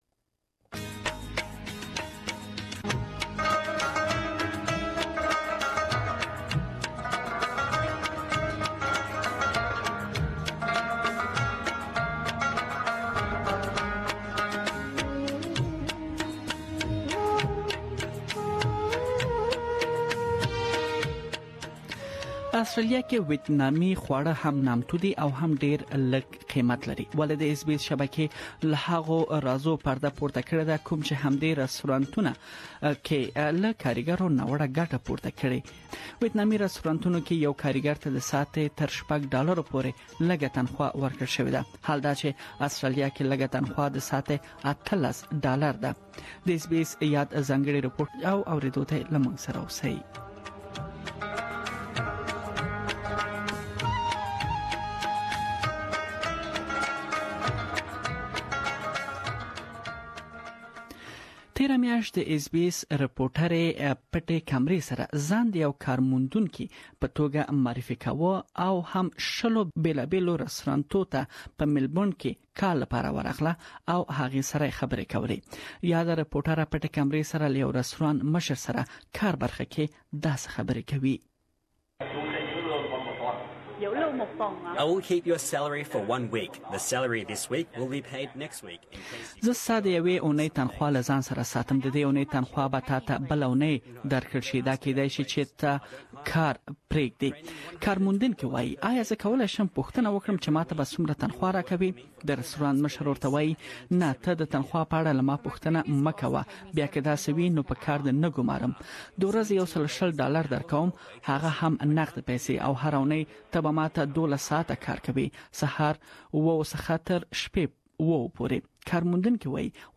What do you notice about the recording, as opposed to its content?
Armed with a hidden camera, this is what the "job seeker" encountered.